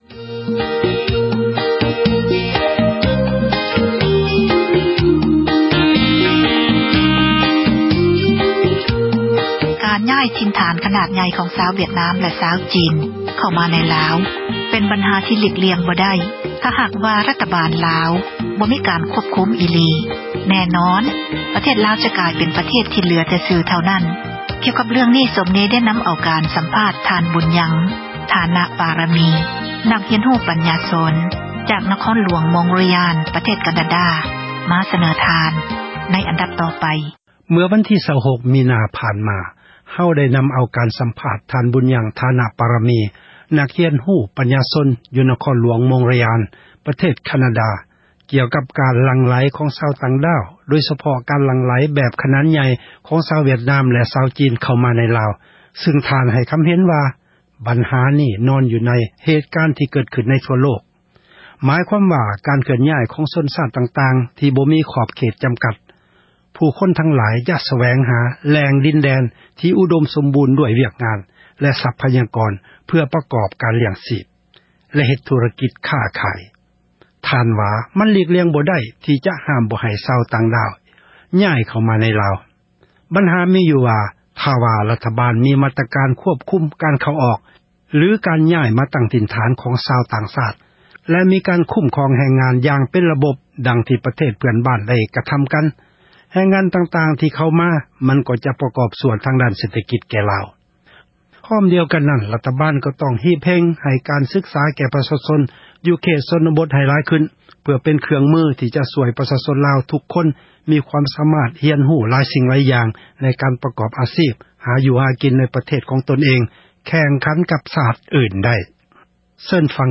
ການ ສັມພາດ